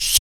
Index of /90_sSampleCDs/ILIO - Vocal Planet VOL-3 - Jazz & FX/Partition H/1 MALE PERC